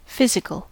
Ääntäminen
Synonyymit checkup check-up physical examination Ääntäminen UK : IPA : /ˈfɪz.ɪ.kəl/ US : IPA : /ˈfɪz.ɪ.kəl/ Haettu sana löytyi näillä lähdekielillä: englanti Määritelmät Adjektiivit Having to do with the body.